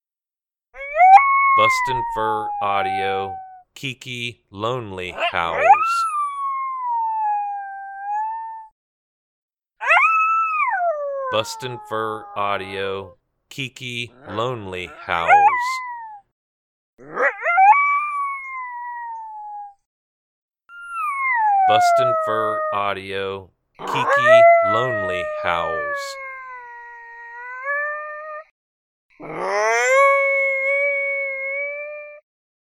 Young Female Coyote sad and lonely howls, great stand starter howl.
BFA KiKi Lonely Howls Sample.mp3